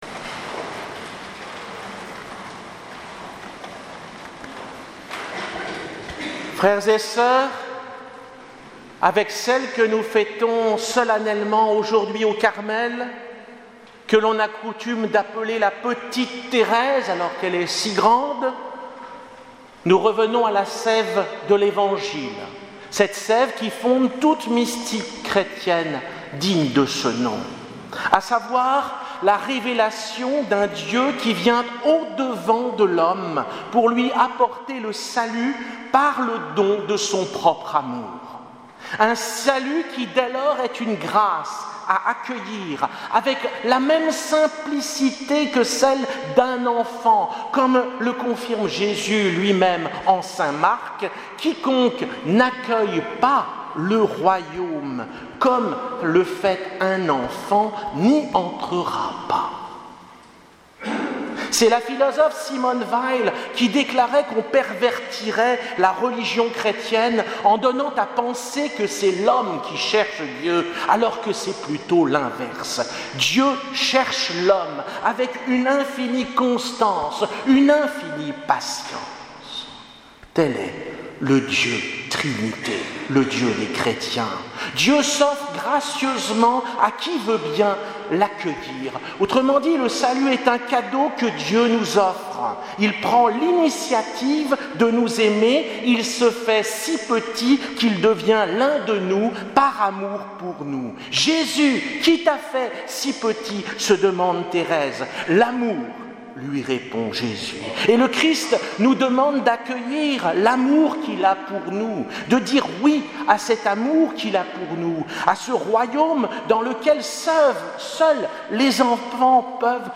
Homélie pour les Vendanges Spirituelles, Le Broussey